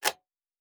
pgs/Assets/Audio/Sci-Fi Sounds/Mechanical/Device Toggle 17.wav at master
Device Toggle 17.wav